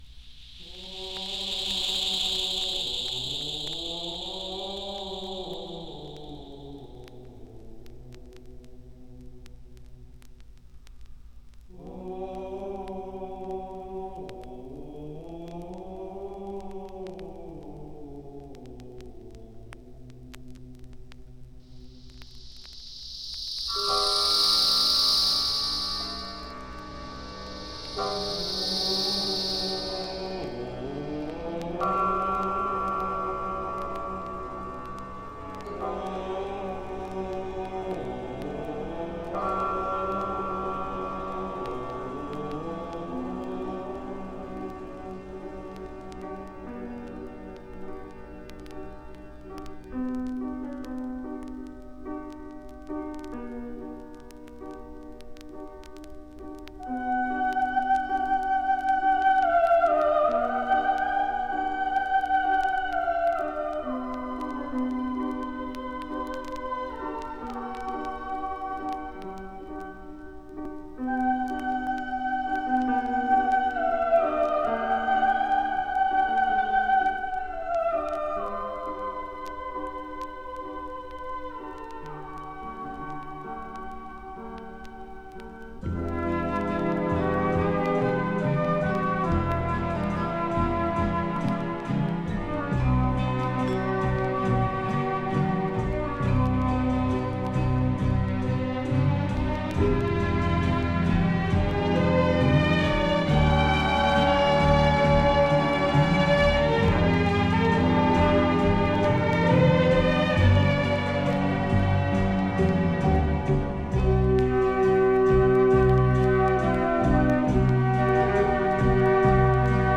And now, a moment of silence followed by a simple space dirge as we ponder todays down grade of our former 9th Planet, Pluto, to a dwarf.
Nocturne.mp3